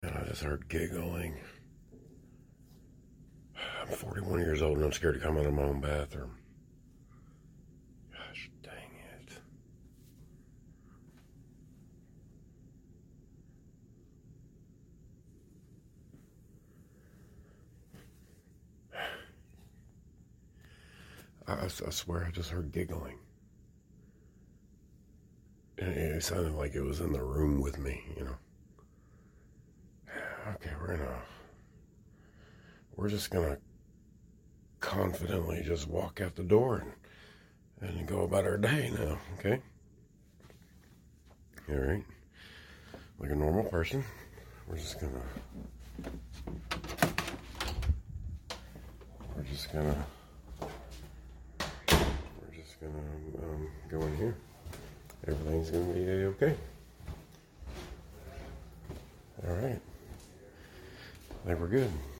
Heard Creepy Giggling In The Sound Effects Free Download